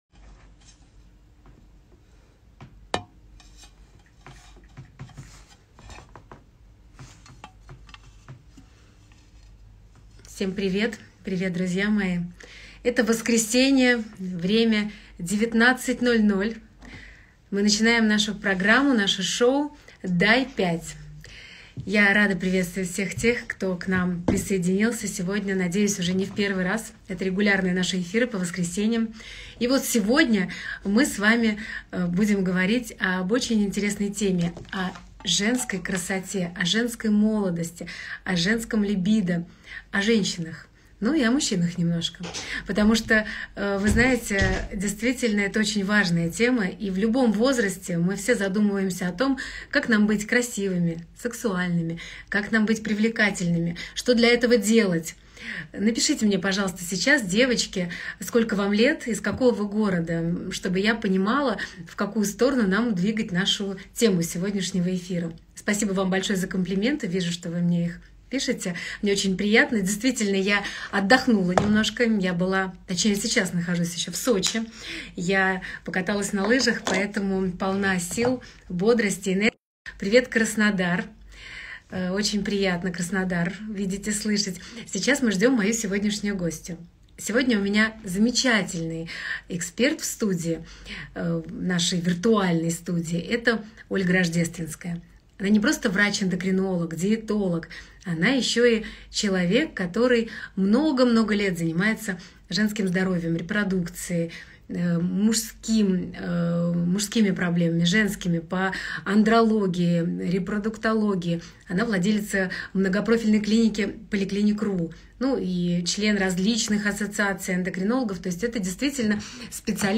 Персональное интервью